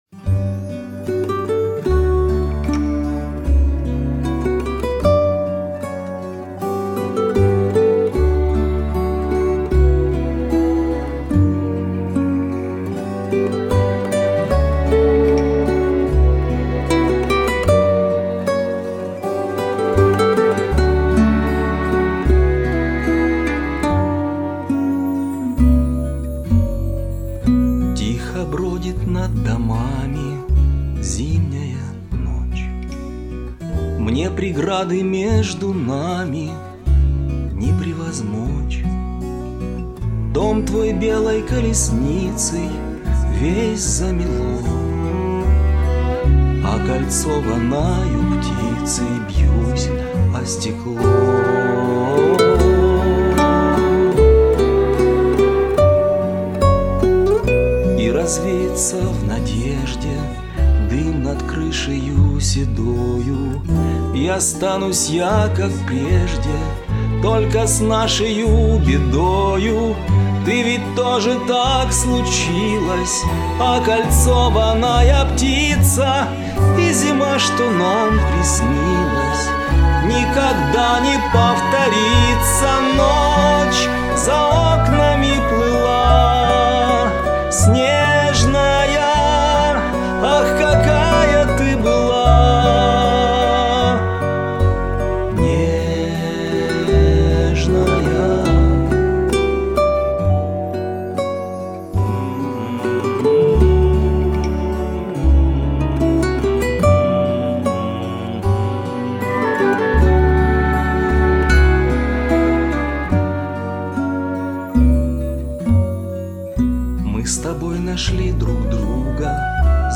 Очень похожи у вас манеры исполнения : интимно и доверительно...и очень нежно поёте !